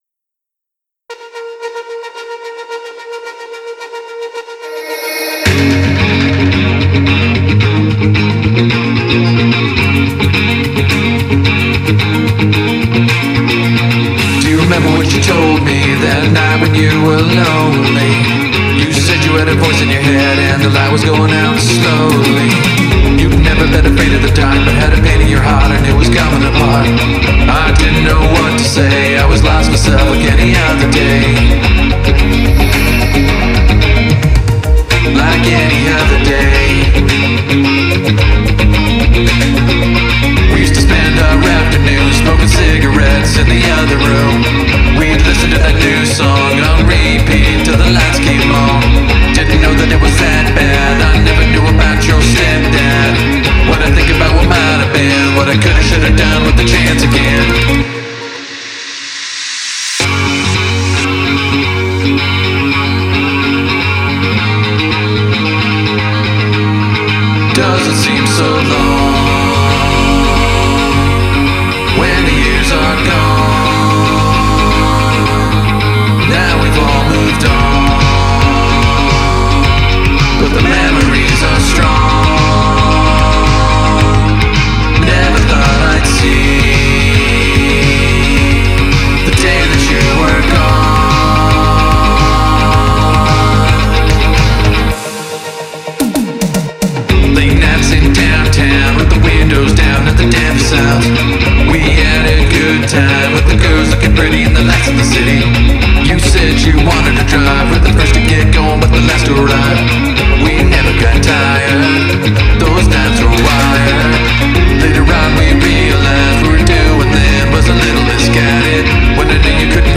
electronic pop song